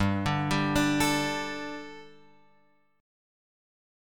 G Suspended 2nd